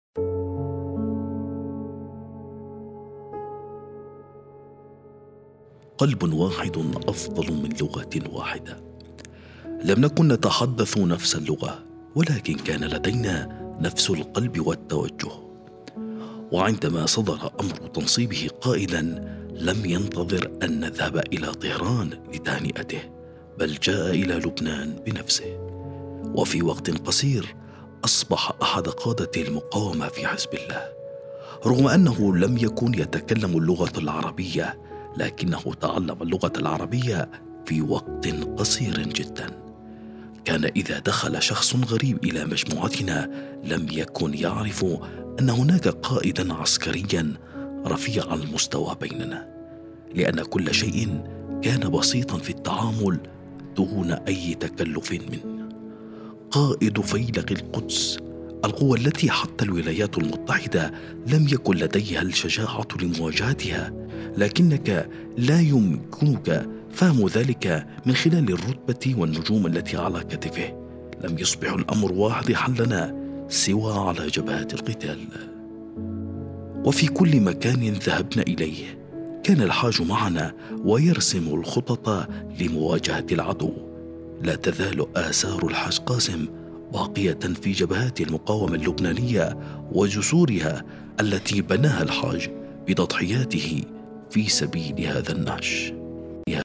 المصدر: مقتطف من كلمة السيد حسن نصر الله الأمين العام لحزب الله في لبنان
في مراسم اليوم السابع لاستشهاد الشهيد الحاج قاسم سليماني وشهداء المقاومة